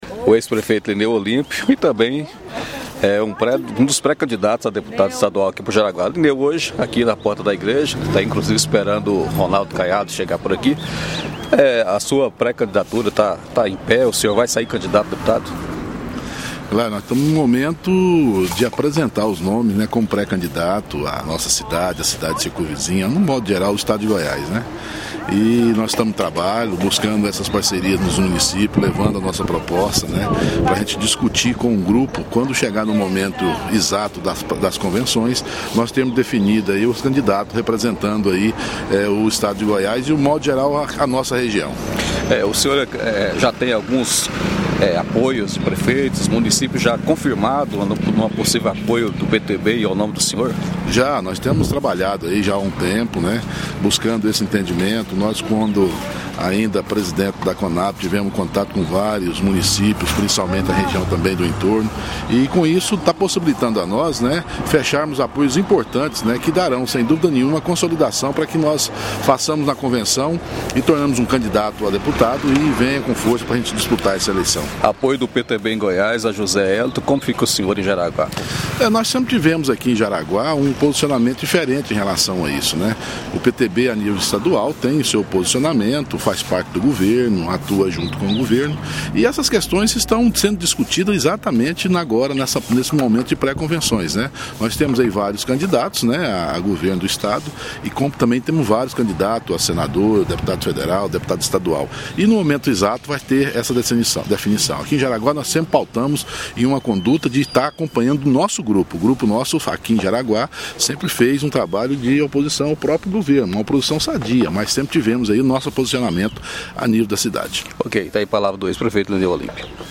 O ex-prefeito Lineu Olímpio de Sousa (PTB), em entrevista ao Jaraguá Notícia, diz que está trabalhando sua base em vários municípios na sua pré-campanha